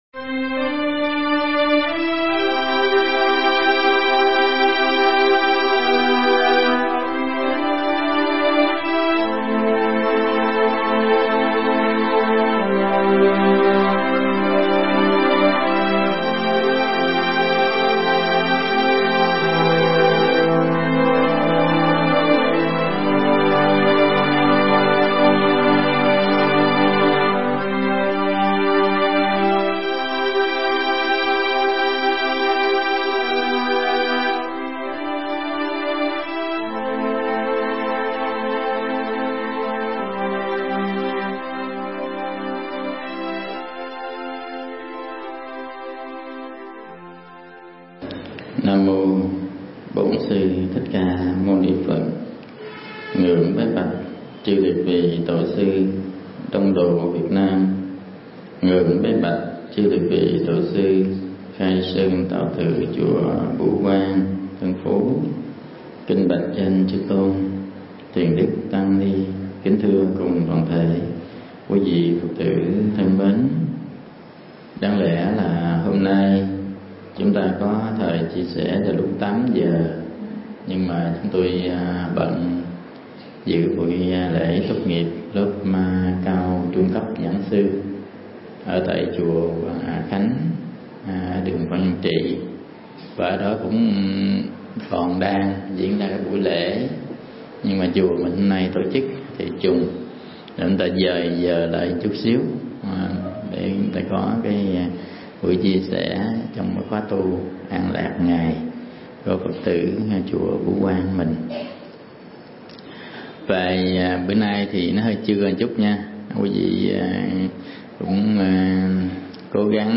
Mp3 Pháp Thoại 5 nguyên tắc cho một đời tu
giảng tại Chùa Bửu Quang, Quận Tân Phú, TP.HCM